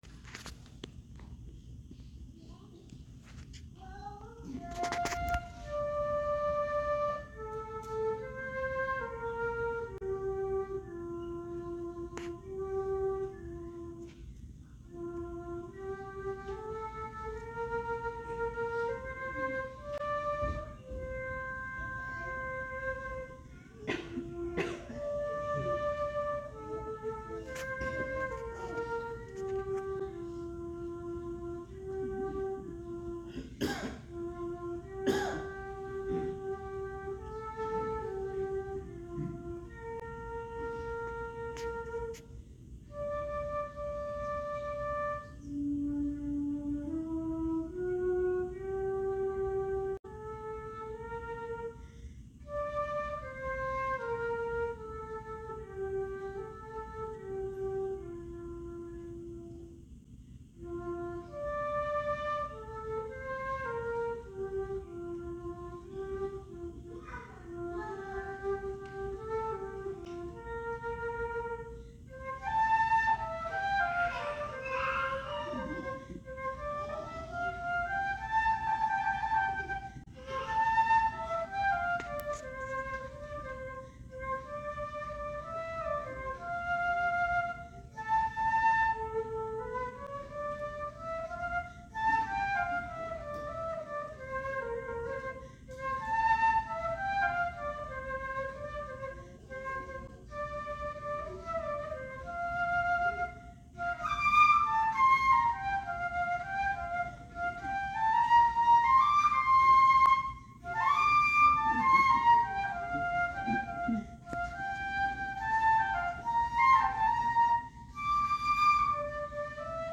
Flute Solo
I love taking hymns and making them a little more interesting. This is meant to be very flexible and expressive so don't feel like you have to stick to exactly what's on the page!
Voicing/Instrumentation: Flute Solo We also have other 35 arrangements of " It Came Upon a Midnight Clear ".